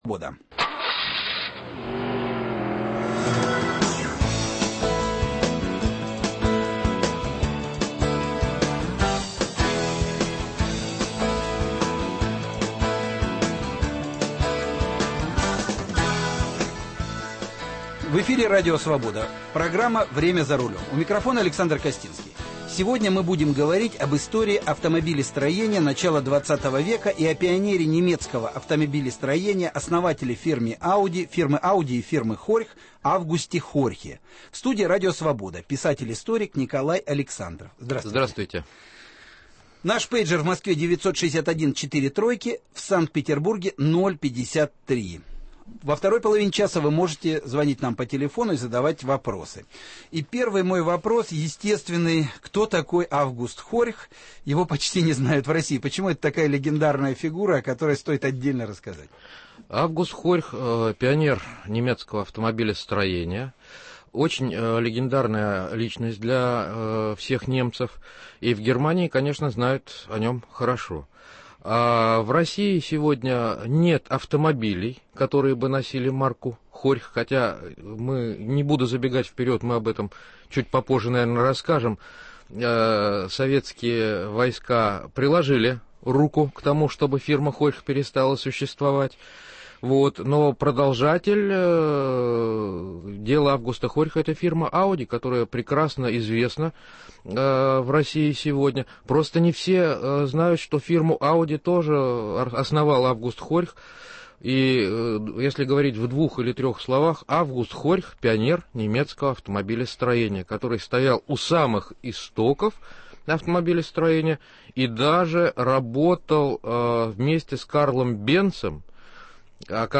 История автомобилестроения начала XX века и пионерия немецкого автомобилестроения, основатель фирмы Audi и фирмы Horch Август Хорьх. В студии Радио Свобода